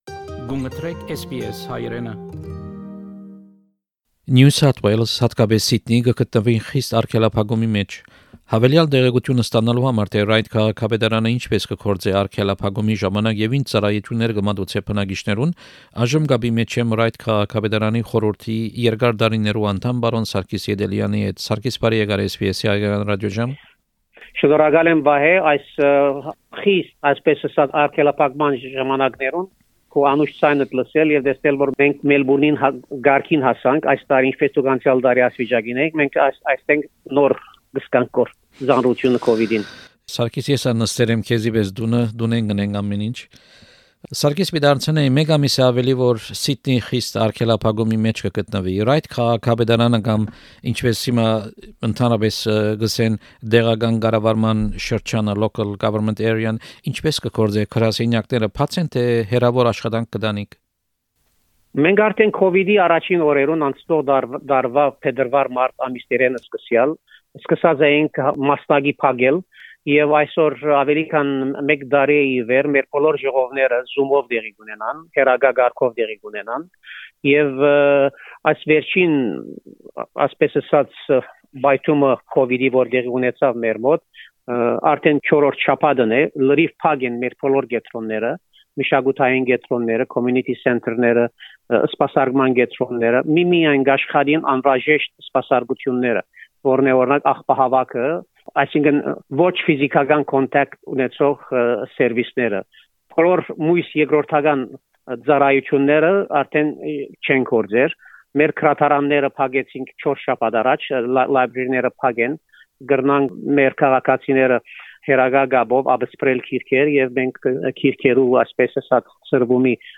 Interview (in Armenian) with City of Ryde Councillor, Mr Sarkis Yedelian OAM. The main topic of discussion is the lockdown in Sydney and Council services during the lockdown.